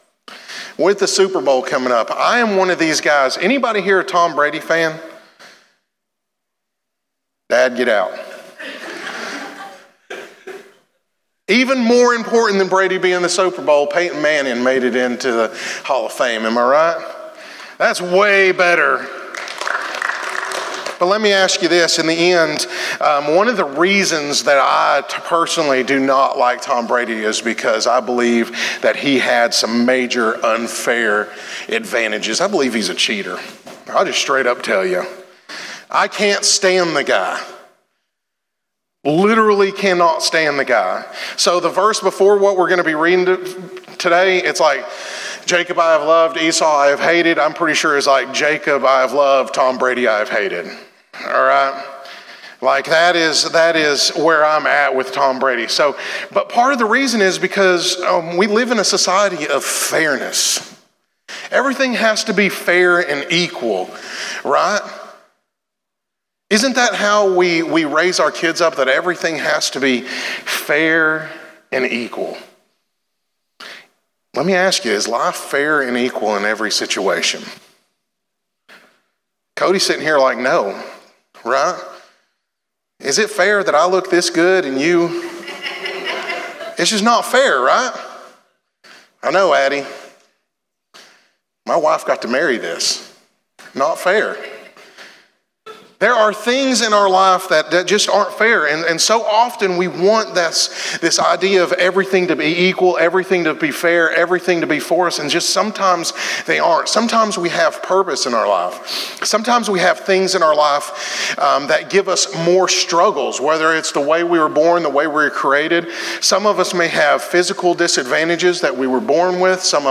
In today's sermon we discuss what that purpose is, and how God uses us for his glory.